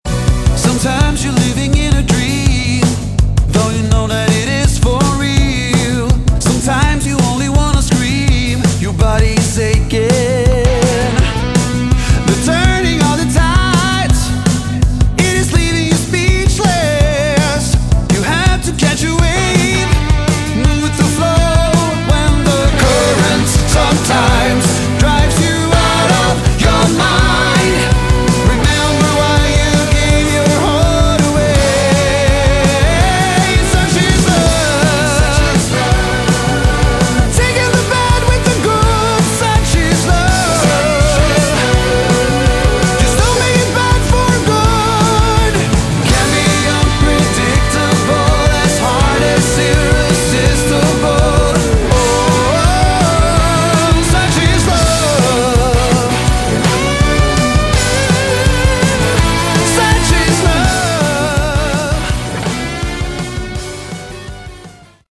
Category: AOR
Vocals
Guitars
Bass
Keyboards
Drums